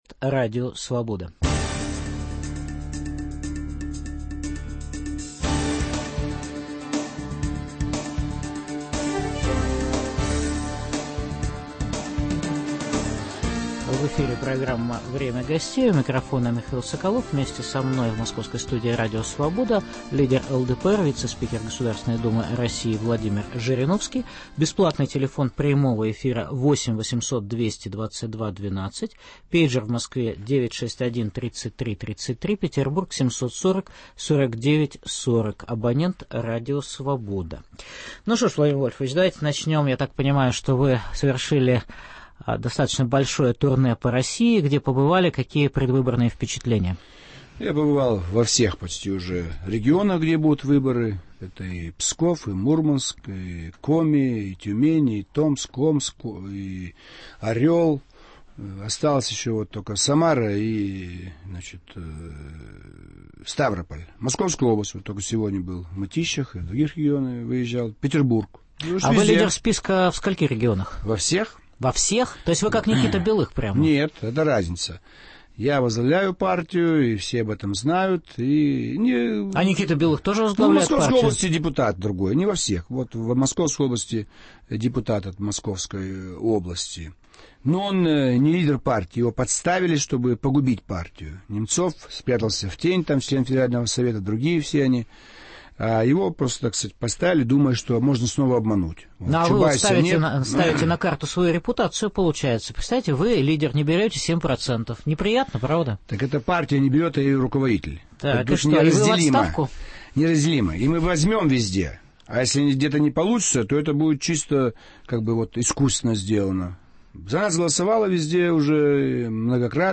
В программе выступит и ответит на вопросы слушателей лидер ЛДПР Владимир Жириновский.